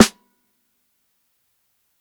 635_SNARE_LOUD.wav